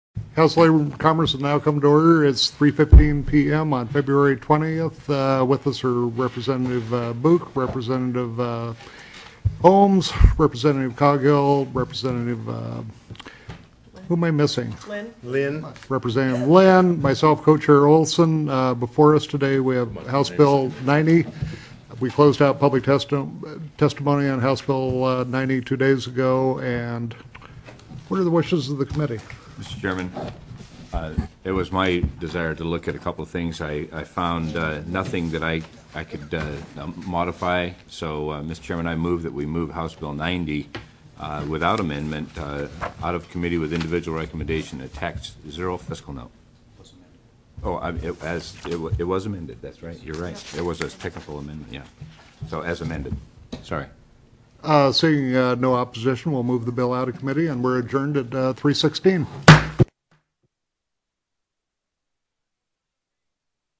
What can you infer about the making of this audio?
+ Bills Previously Heard/Scheduled TELECONFERENCED